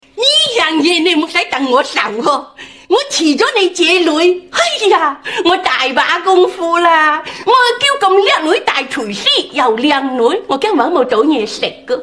剧中靓女阿娇的口音是广东高州话版粤语，又给人一种化州话版粤语的感觉，不知道这种口味GLM‑TTS能否适应。